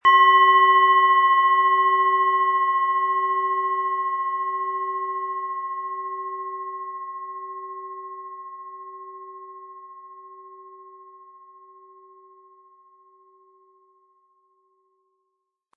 DNA & Jupiter Klangschale, Ø 12,2 cm im Sound-Spirit Shop | Seit 1993
• Tiefster Ton: Jupiter
Um den Originalton der Schale anzuhören, gehen Sie bitte zu unserer Klangaufnahme unter dem Produktbild.
PlanetentöneDNA & Jupiter
HerstellungIn Handarbeit getrieben
MaterialBronze